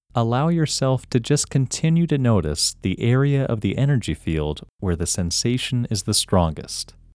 IN – First Way – English Male 8
IN-1-English-Male-8.mp3